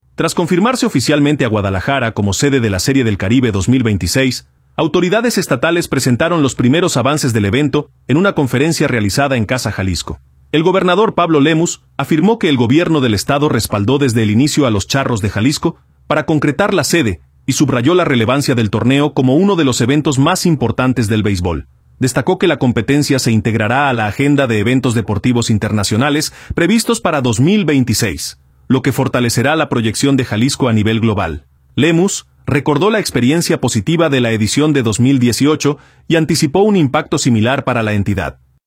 Tras confirmarse oficialmente a Guadalajara como sede de la Serie del Caribe 2026, autoridades estatales presentaron los primeros avances del evento en una conferencia realizada en Casa Jalisco. El gobernador Pablo Lemus afirmó que el Gobierno del Estado respaldó desde el inicio a los Charros de Jalisco para concretar la sede y subrayó la relevancia del torneo como uno de los eventos más importantes del beisbol.